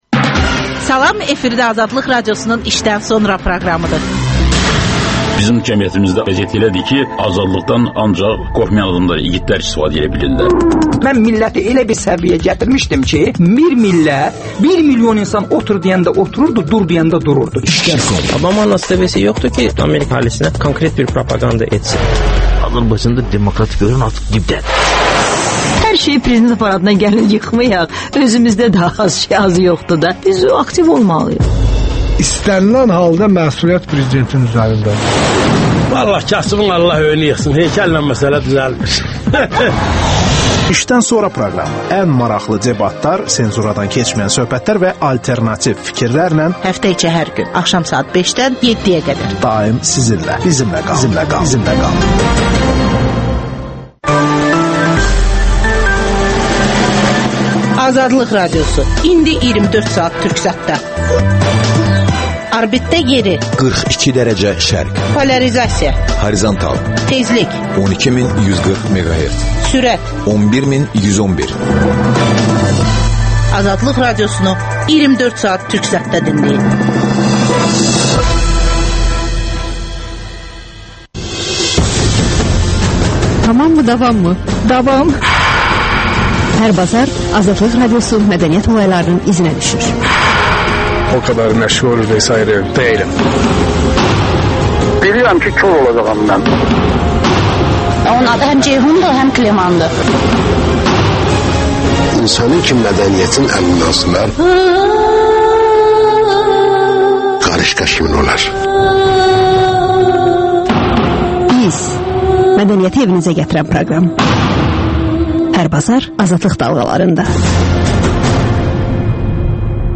Deputat Elmira Axundova suallara cavab verir